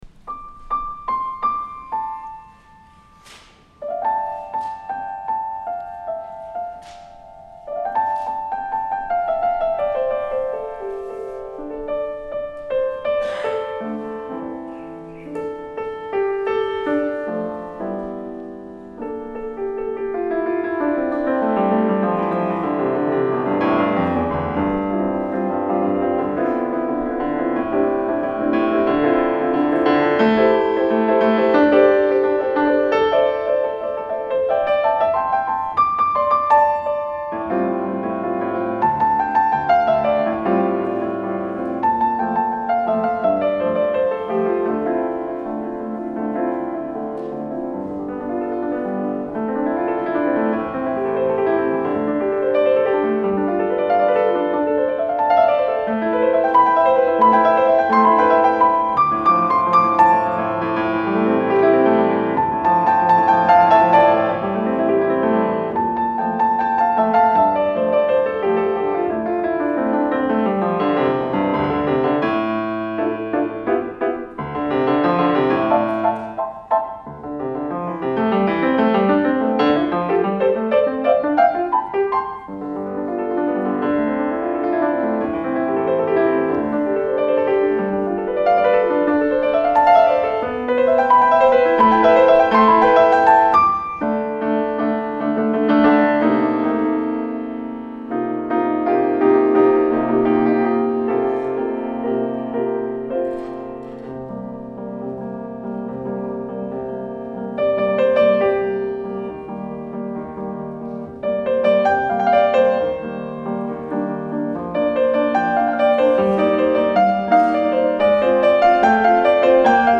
for Piano (2005)
piano.